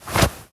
foley_object_push_pull_move_03.wav